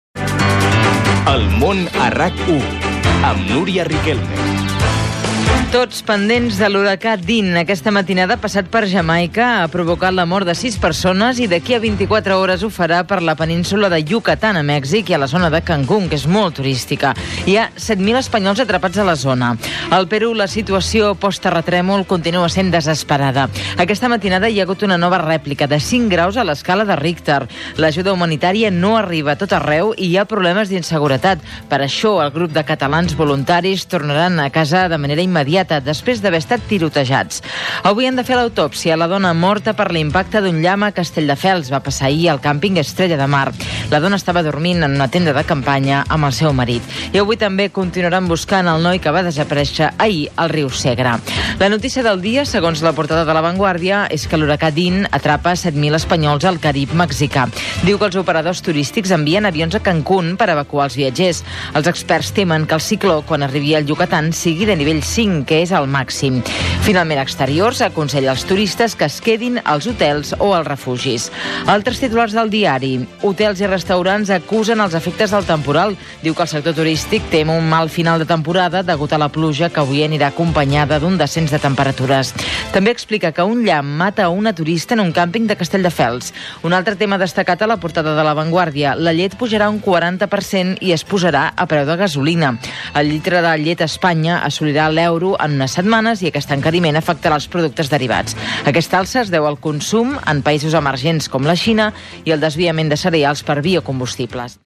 Indicatiu del programa i sumari informatiu: conseqüències de l'huracà Dean, una dona morta a Castelldefels per un llamp, etc.
Info-entreteniment